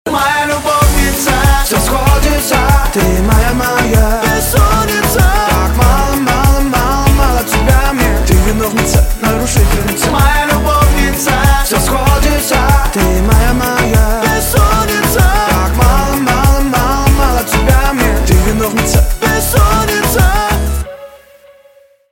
• Качество: 320, Stereo
поп
мужской вокал
dance